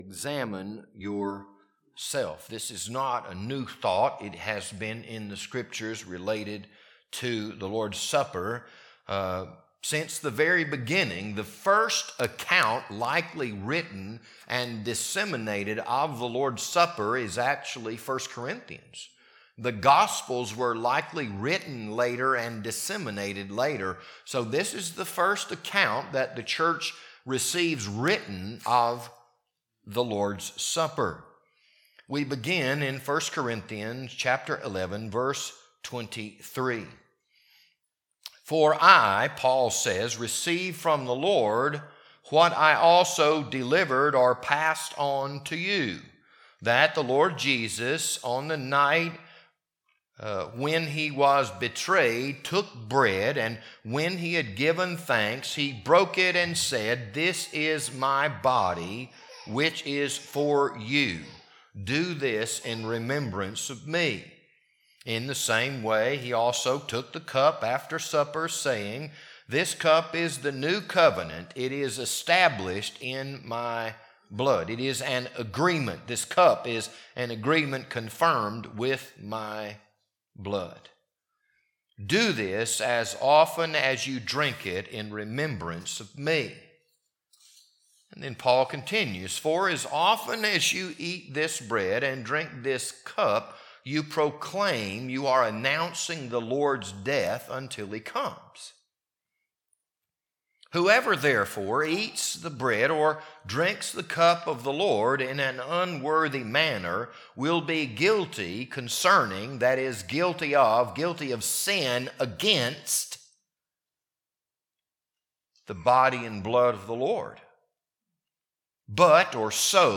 This sermon was recorded for April 2nd, 2025, Maundy Thursday.